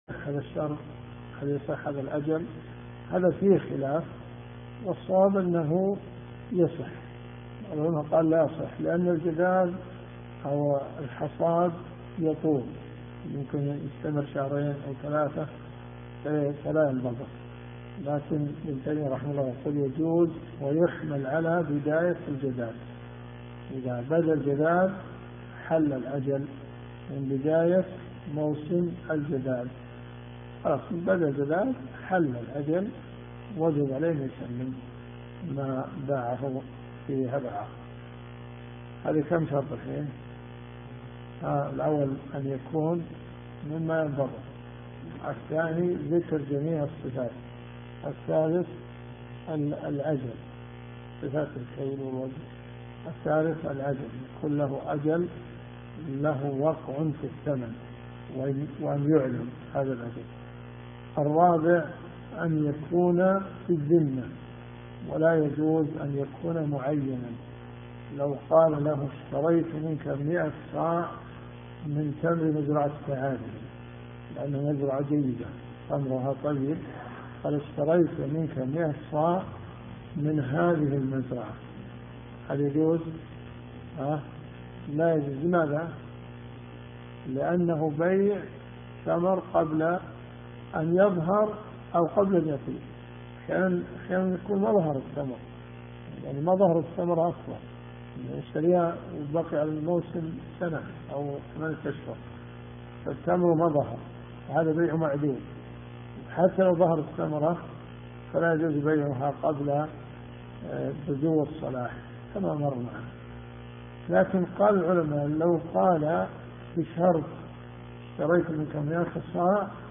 صحيح مسلم . كتاب المساقاة والمزارعة . تتمة حديث 4118 -إلى- حديث 4121 . اذا الصوت ضعيف استخدم سماعة الاذن